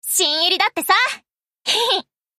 Ship Voice Phoenix Construction.mp3